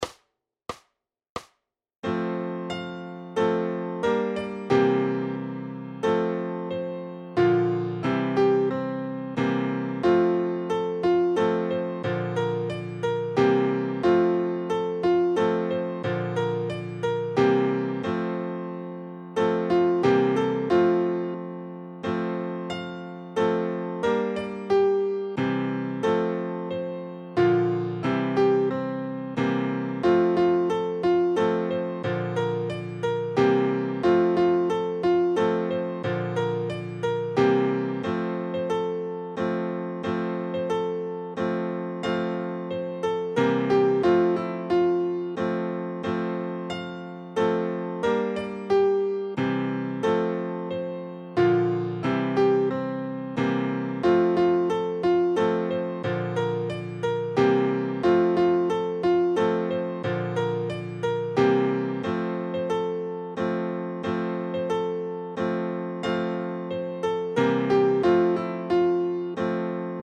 Noty na snadný klavír.
Aranžmá Noty na snadný klavír
Hudební žánr Vánoční písně, koledy